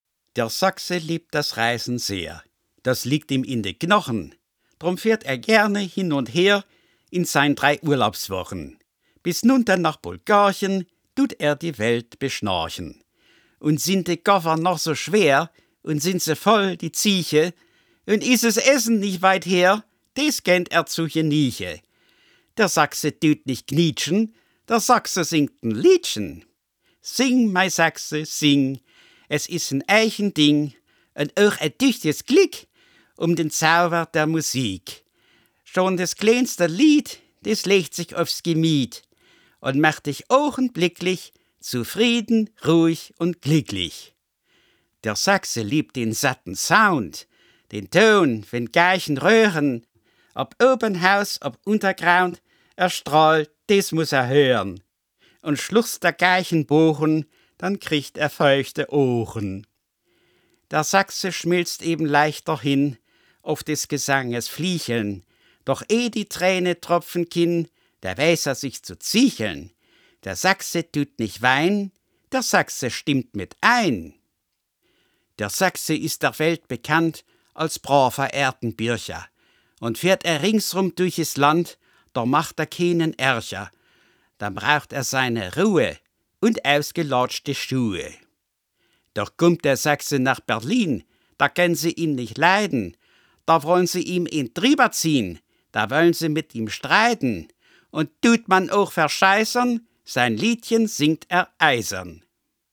Hörproben Dialekte
Sächsisch